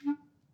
Clarinet
DCClar_stac_D3_v1_rr2_sum.wav